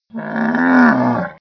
c_camel_atk2.wav